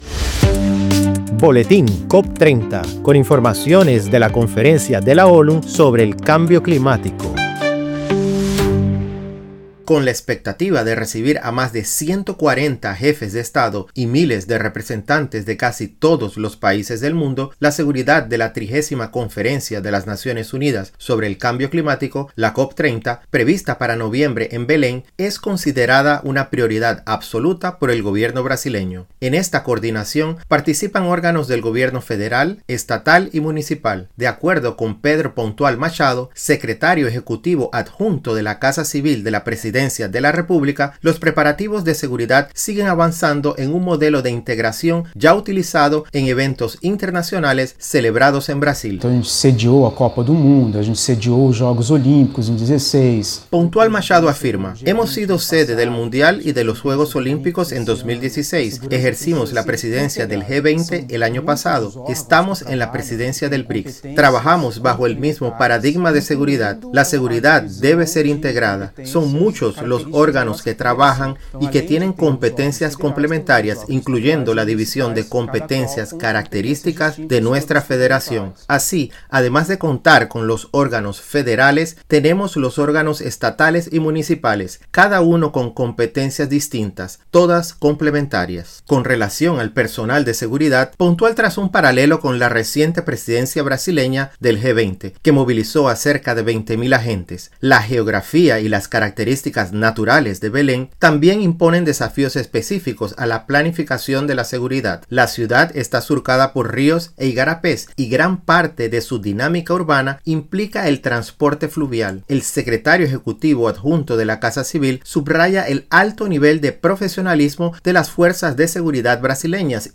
Las fuerzas de seguridad aplicarán en la COP30 el mismo modelo utilizado durante la Copa del Mundo, los Juegos Olímpicos y el G20, con agentes dedicados a la protección de líderes mundiales y de la población de Belém. La operación incluye la ciberseguridad y los controles aéreo y fluvial, garantizando una seguridad integrada. Escuche el reportaje y entérese de más detalles.